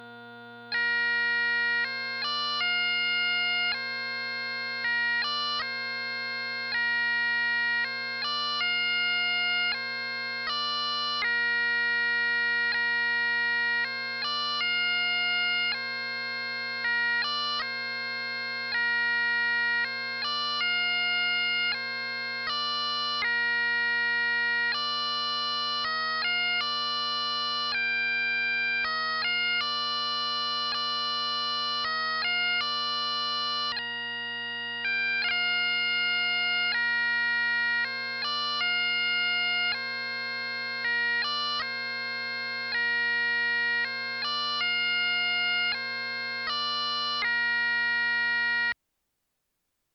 Suo Gan | Great Falls Pipe Band